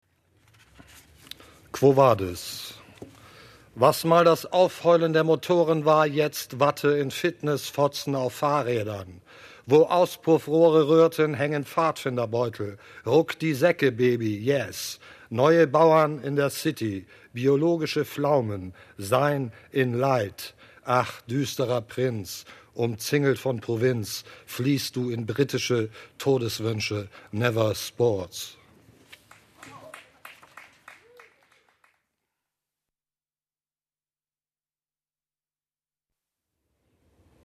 Lesung von Kiev Stingl in der literaturWERKstatt Berlin zur Sommernacht der Lyrik – Gedichte von heute